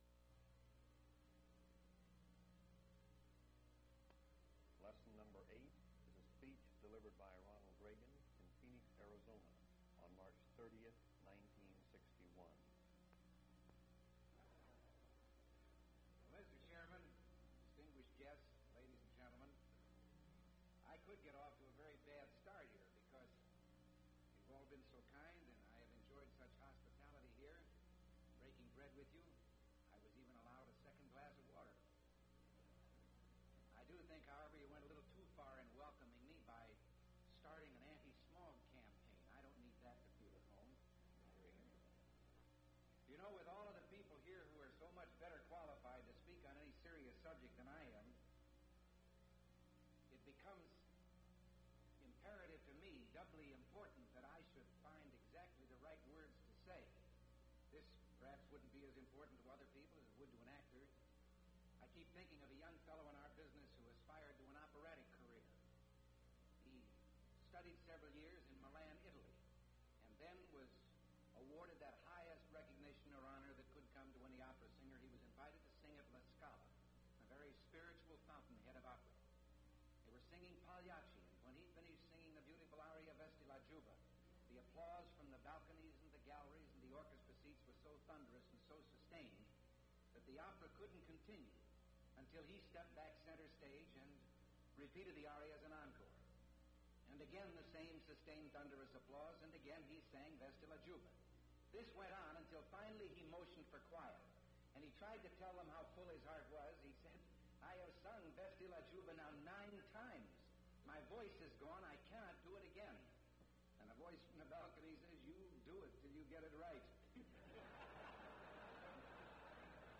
Ronald Reagan’s speech to Chamber of Commerce Annual Meeting in Phoenix, Arizona, (subject: Encroaching Control)
Lesson 8, Reel to Reel Audio Format MP3 Audio file